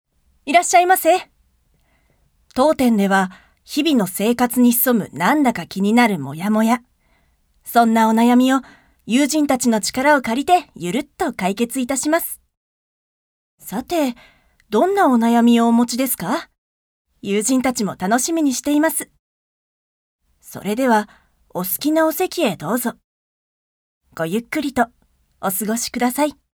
ボイスサンプル、その他
ナレーション１